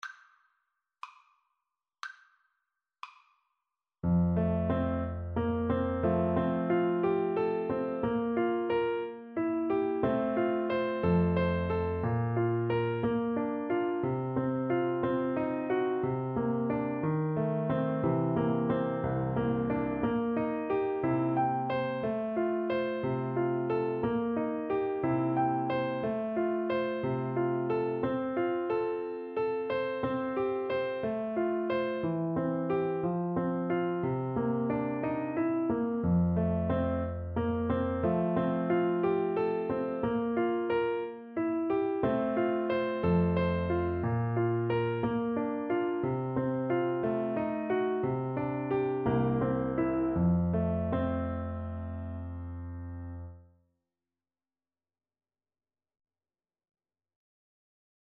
Play (or use space bar on your keyboard) Pause Music Playalong - Piano Accompaniment Playalong Band Accompaniment not yet available transpose reset tempo print settings full screen
F major (Sounding Pitch) (View more F major Music for Violin )
~ = 60 Andantino (View more music marked Andantino)
Classical (View more Classical Violin Music)